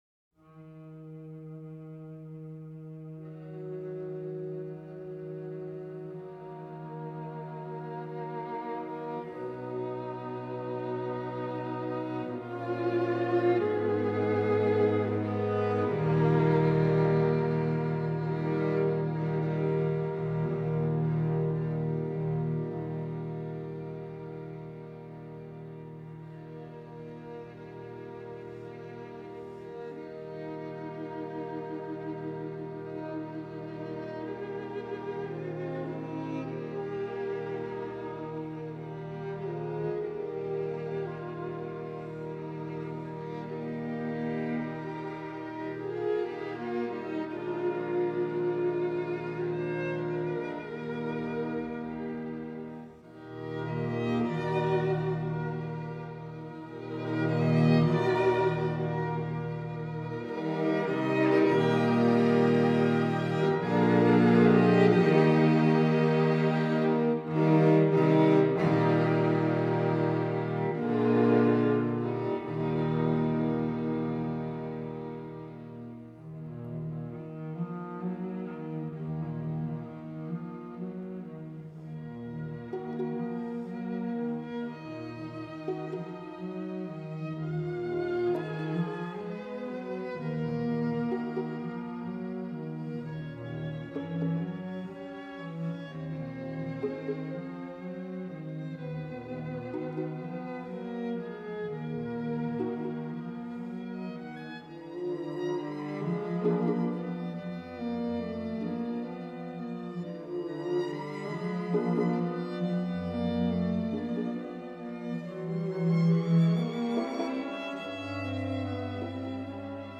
Dvorak, String Quartet #13 In G, Op. 106, B 192 – 2. Adagio Ma Non Troppo